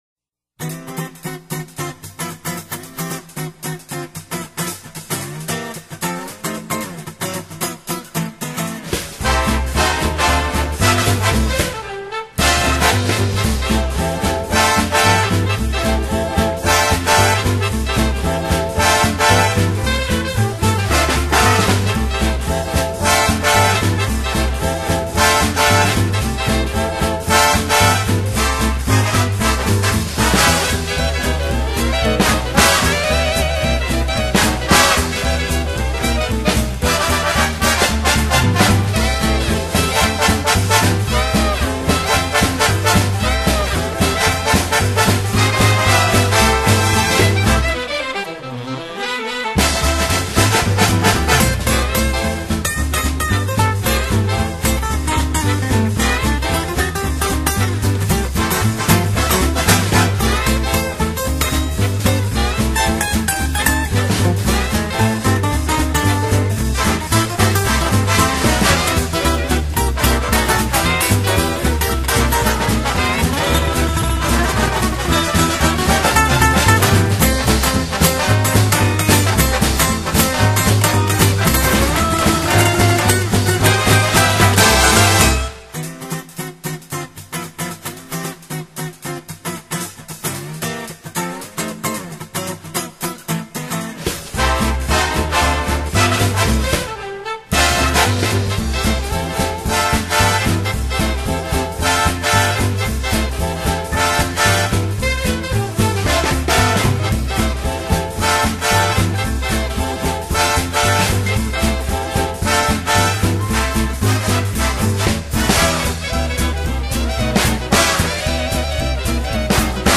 Lindy Hop Music
[Intro-4 x 8 count around 17 seconds-no dancing but moving]